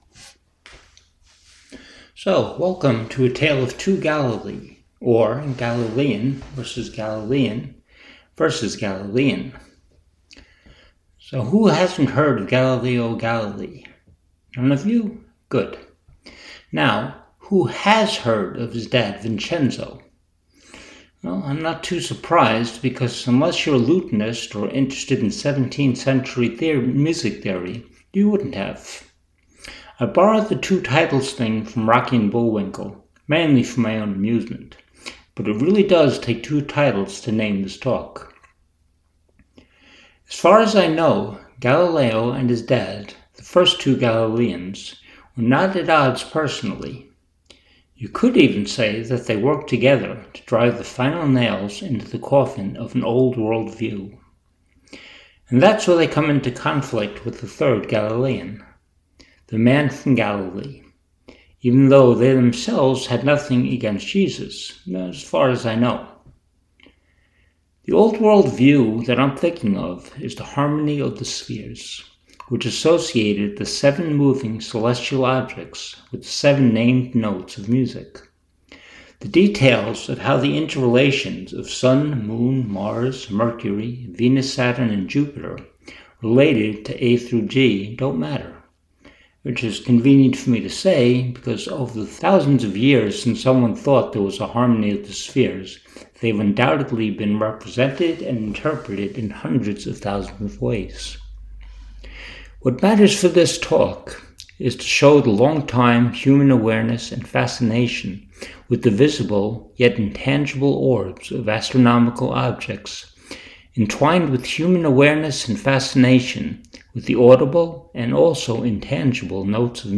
Here are a PDF file of the text and an mp3 of me reading it. You'll want to read along while you listen since I have a tendecy to mumble and swallow words.